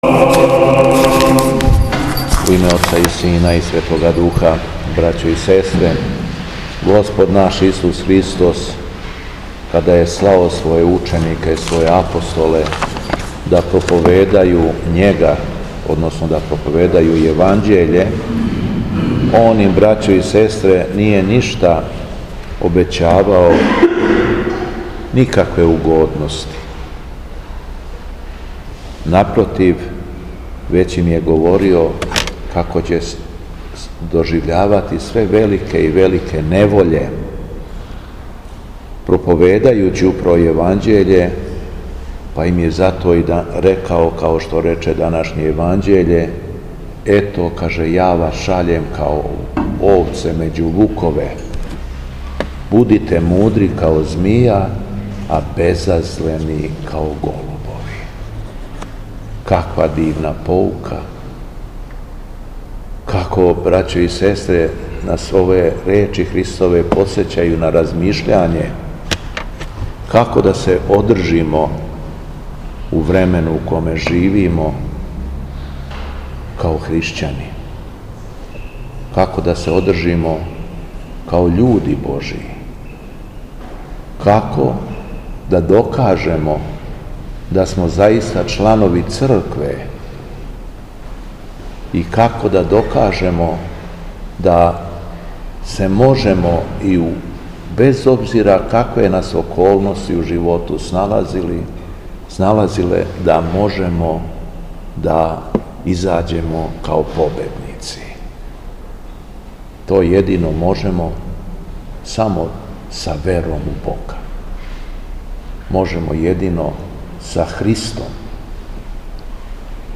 Беседа Његовог Високопреосвештенства Митрополита шумадијског г. Јована
После прочитаног јеванђелског зачала, Високопреосвећени Митрополит се обратио беседом сабраном народу: